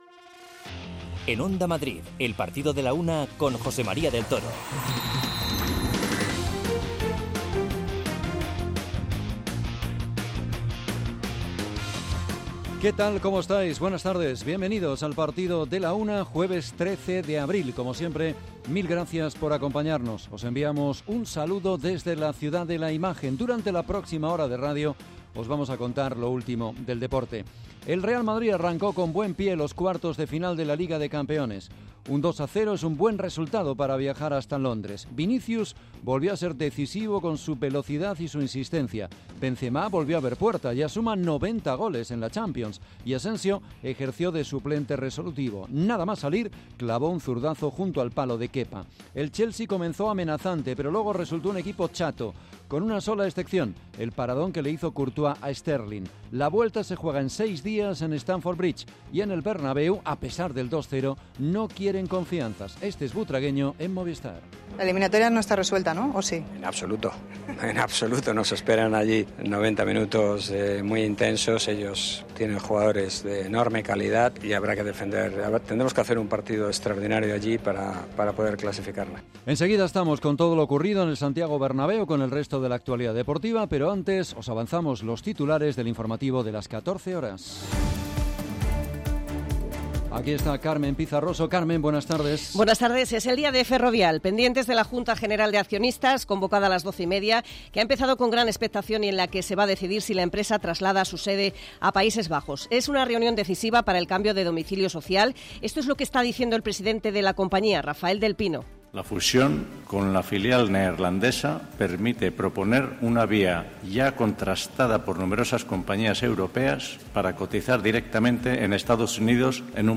Escuchamos a un sincero y reflexivo Vinicius, que fue elegido como mejor jugador del partido.